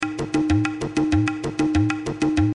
Tag: 96 bpm Ethnic Loops Tabla Loops 434.81 KB wav Key : Unknown